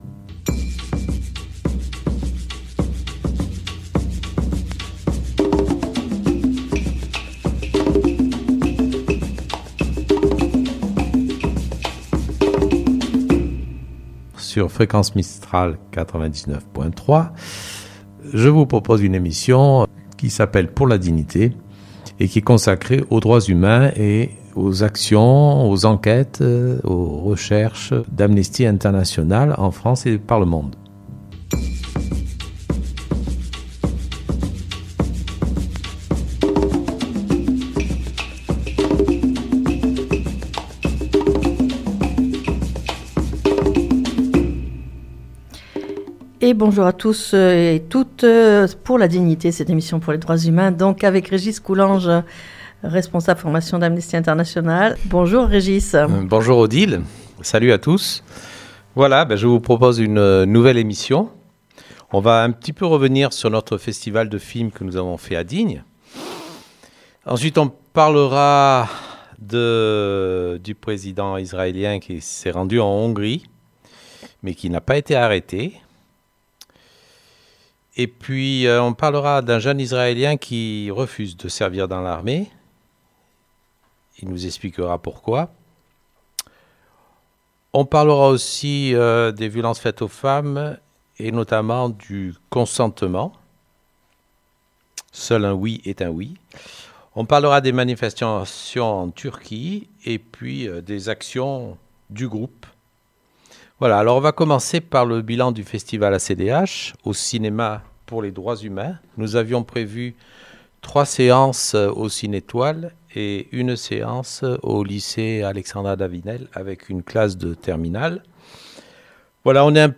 Bilan du festival ACDH Interview